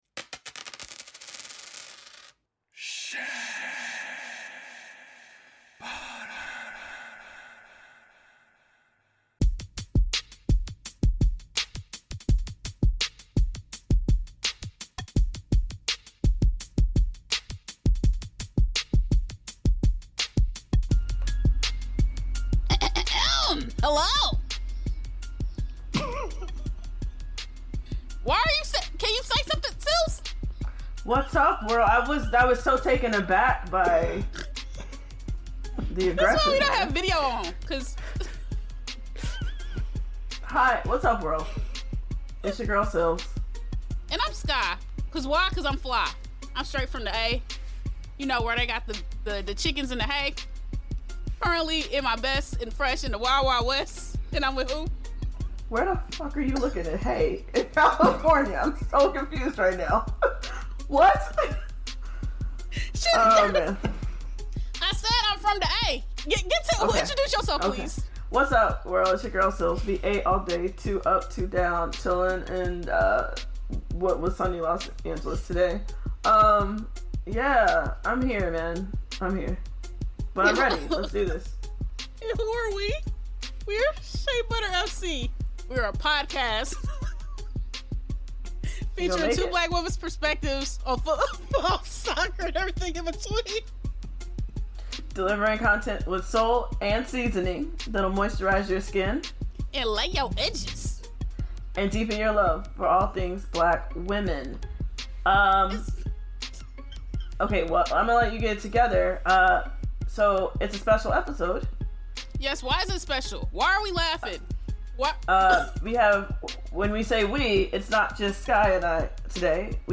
Lastly, we drop our collective Hit and Rifts of the week and drop a little bit of karaoke that may be off key!.